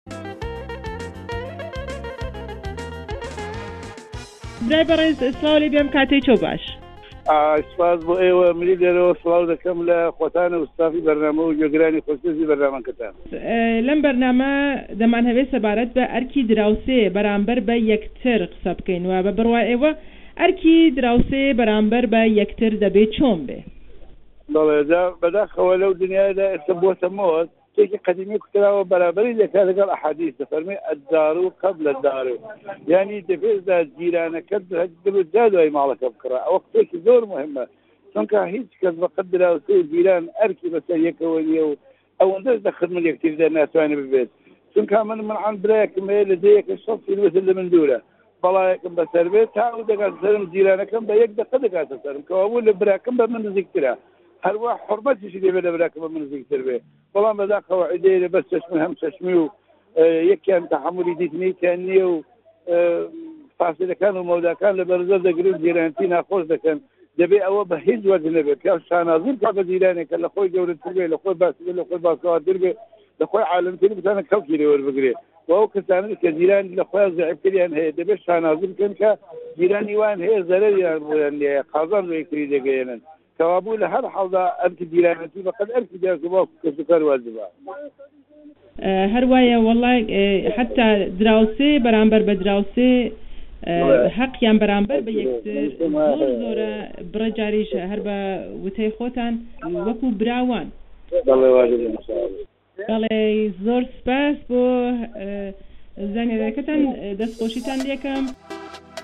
ڕاپۆرتێک سەبارەت به ئەرکی دراوسێ بەرامبەر به یەکتر